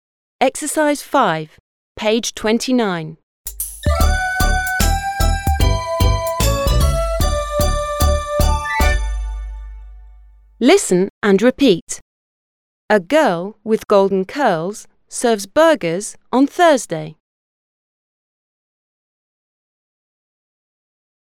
A girl with golden curls serves burgers on Thursday. – [Э гё:л уиз’ голдэн кё:лз сё:вз бё:гиз он с’ёздэй] – Девушка с золотистыми локонами подает гамбургеры по четвергам.